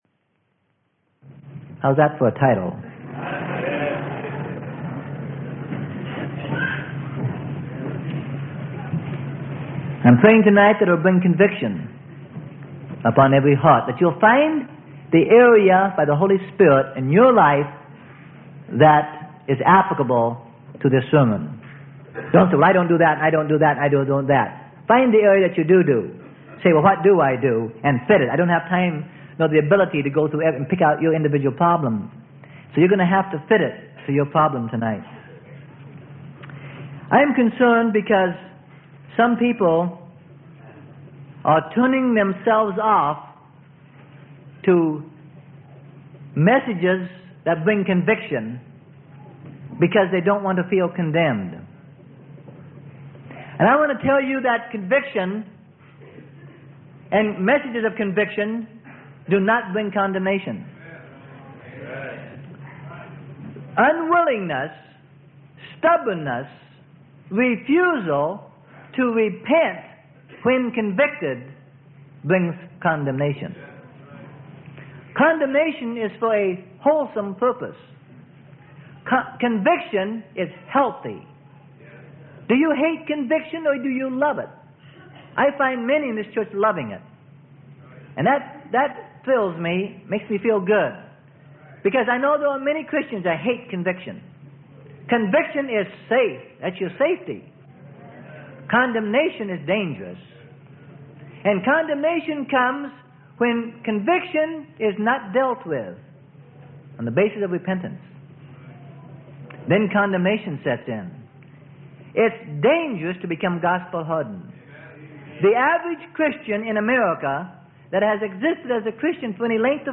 Sermon: Lies, Lies, Lies.